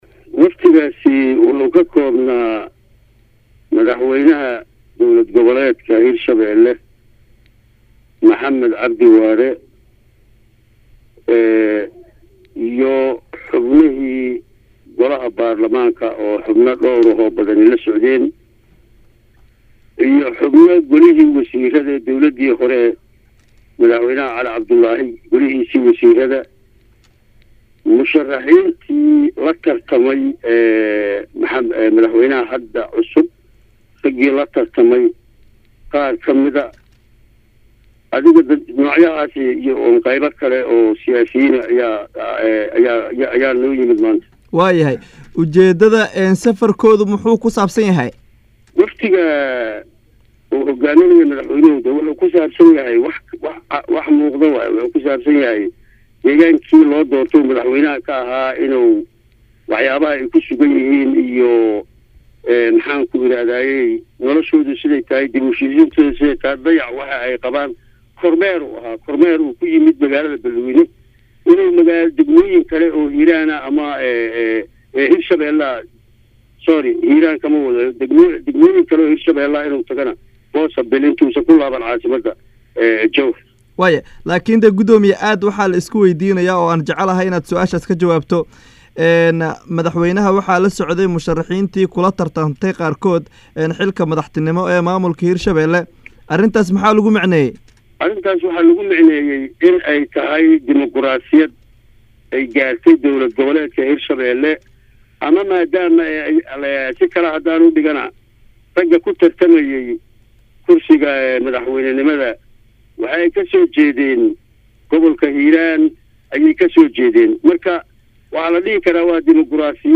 Cali Jayte Cismaan Gudoomiyaha Cusub ee Gobolka Hiiraan kana mid ah Gobolada hoos yimaada Maamulka hirshabeele oo la hadlay Radio muqdisho Codka jahmuuriyada Soomaaliya ayaa sheegay in maamulka Gobolka Hiiraan xooga Saari Horumarinta kaabayaasha dhaqaalaha sida dhismaha wadooyinka Hiiraan iyo sidii maleeshiyaadka Al shabaab looga sifayn lahaa Gobolka.
waraysi-gudoomiyaha-gobolka-hiiraan-cali-jayte-cismaan.mp3